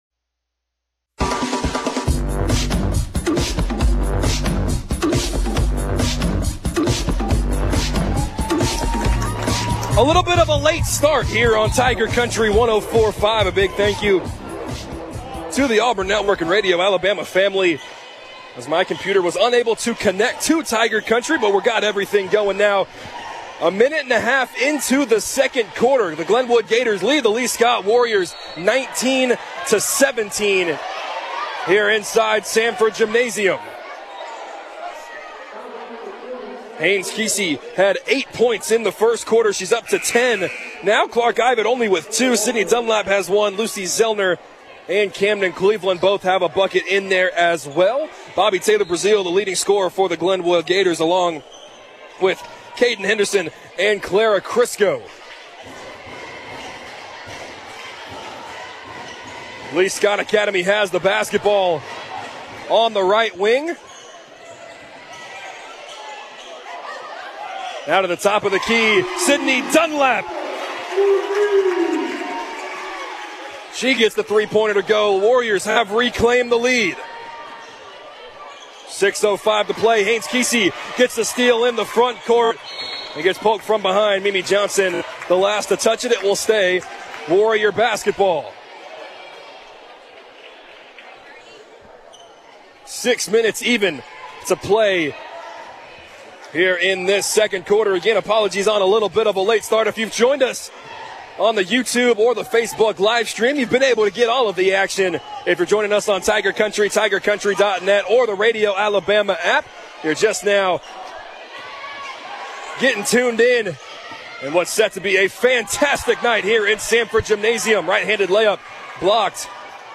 calls Lee-Scott Academy's game versus the Glenwood Gators in the Area Championship game. The Warriors won 50-49.